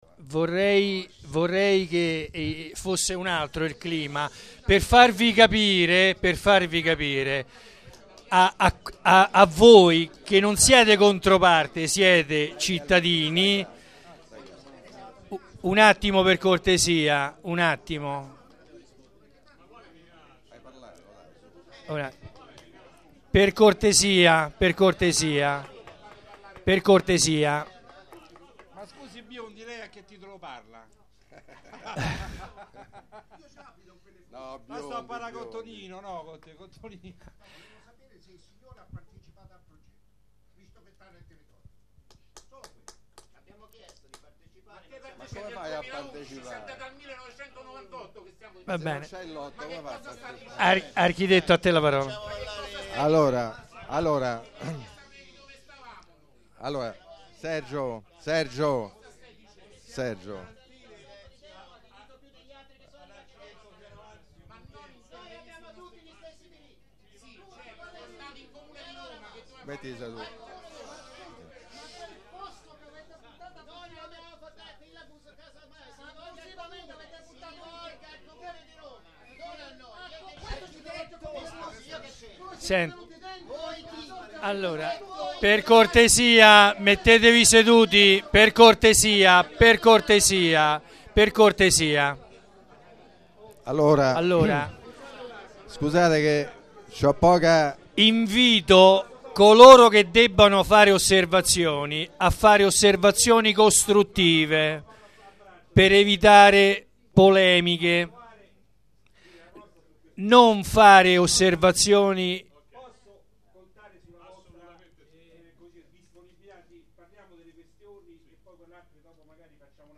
Assemblea
Registrazione integrale dell'incontro svoltosi il 3 aprile 2013 presso la sala consiliare del Municipio Roma XIX
07-discussione.mp3